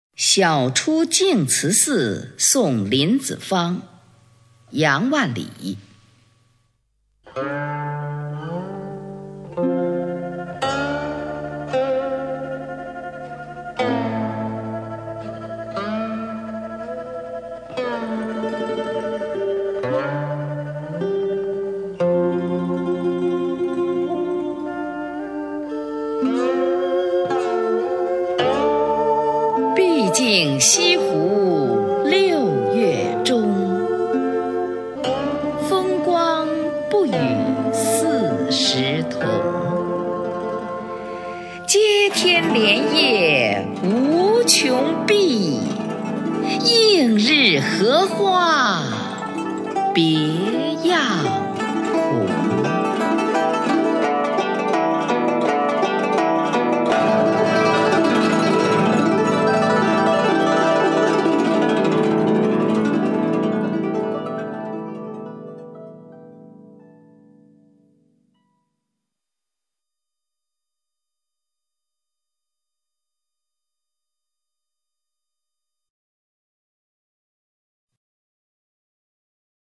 [宋代诗词诵读]杨万里-晓出净慈寺送林子方 宋词朗诵